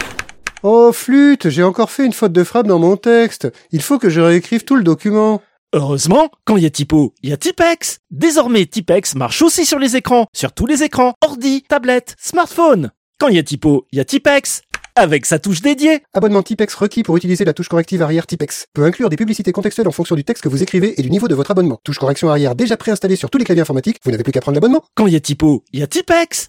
Chroniques › Fausse publicité
Extrait de l'émission CPU release Ex0241 : lost + found (avril 2026).
[Voix rapide] — Abonnement Typp-ex requis pour utiliser la touche correction arrière Typp-ex.